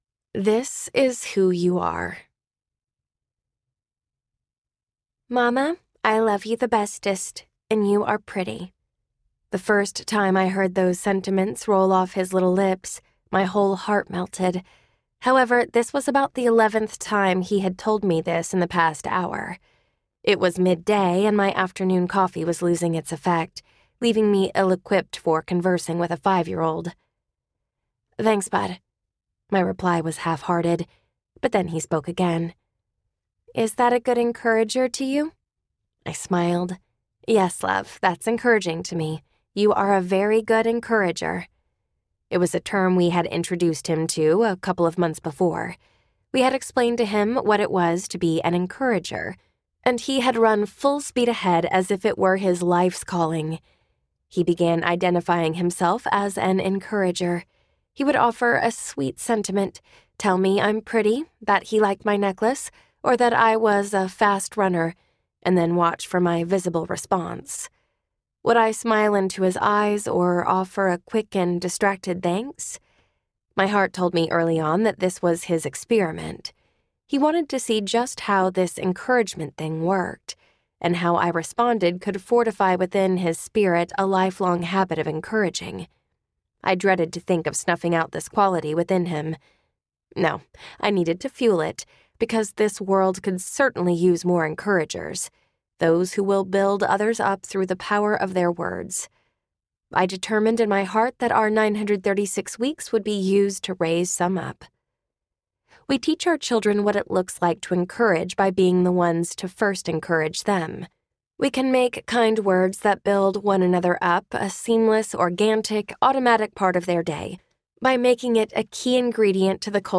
936 Pennies Audiobook
Narrator
6.95 Hrs. – Unabridged